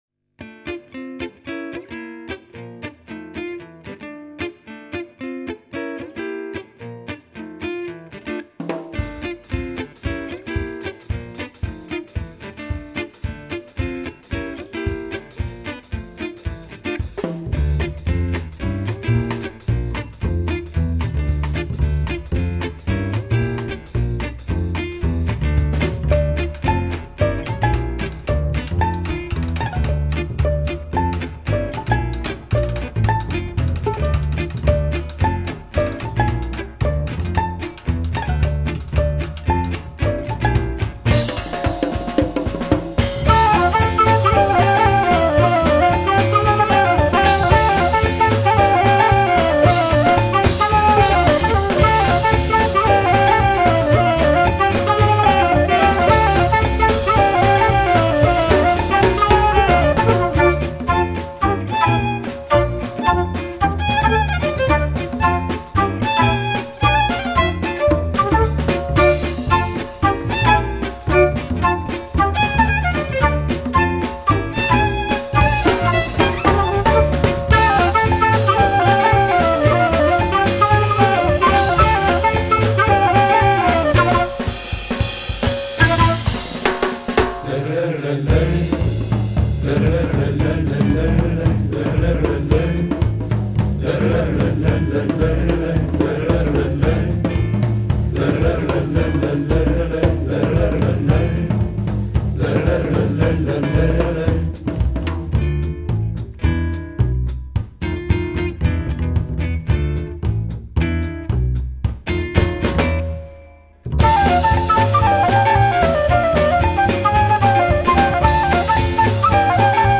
chitarra e voce
voce e sonagli
contrabbasso
violino
flauto traverso, clarinetto, chalumeaux, chitarra ac.
batteria, percussioni, marimba, fisarmonica, piano
tapan, darabukka, tamburello, tabla, percuss. e colori